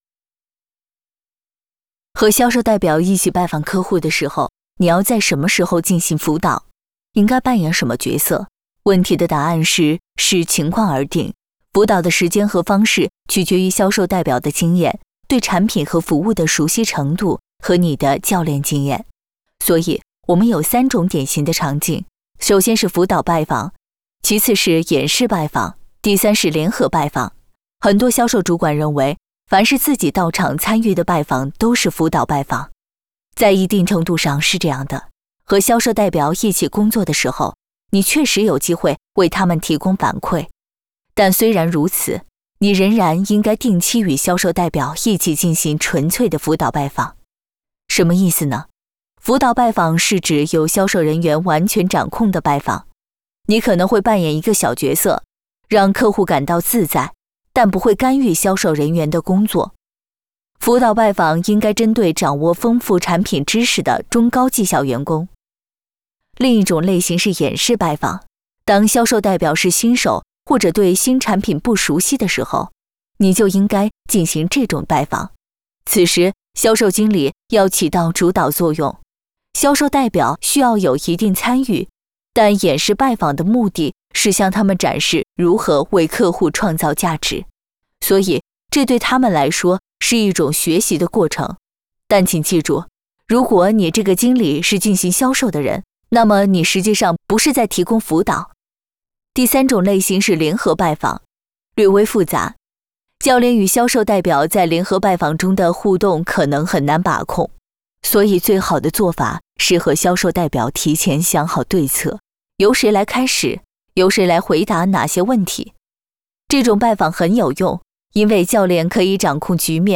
Chinese_Female_018VoiceArtist_8Hours_High_Quality_Voice_Dataset